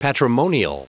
Prononciation du mot patrimonial en anglais (fichier audio)
Prononciation du mot : patrimonial